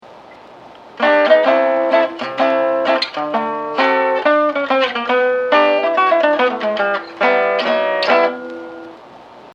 combine chords and single string fills over the four bar chord progression: Am/// D7/// G/// G///